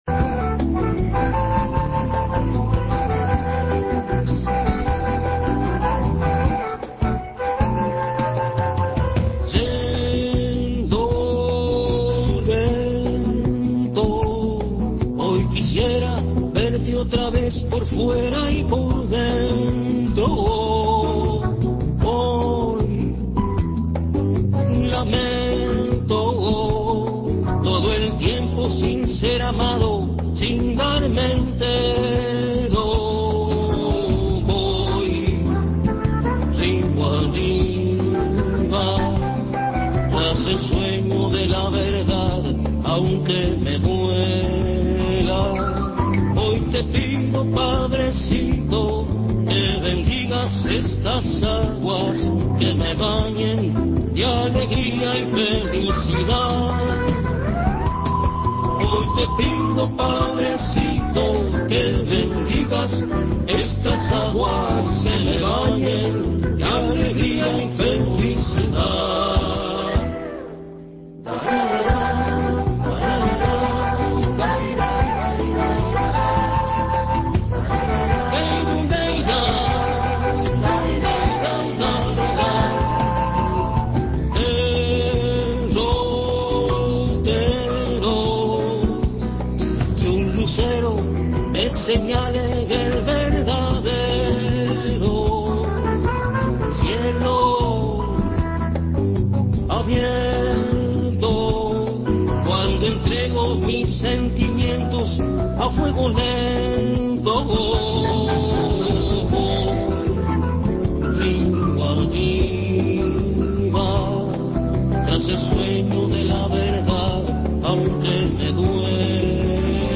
El cantante visitó Café Torrado y habló de su historia, sus comienzos y las expectativas que tiene con su nueva agrupación. Además de mostrar un poco de su música.